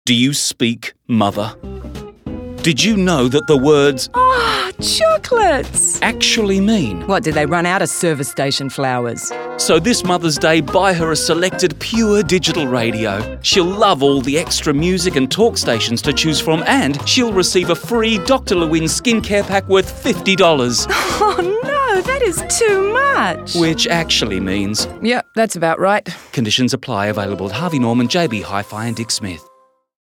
The four 30 second radio ads promote digital radios as the perfect gift and the retailer’s special offer of a free Dr Lewinn skincare pack when one of three selected Pure digital radios are purchased.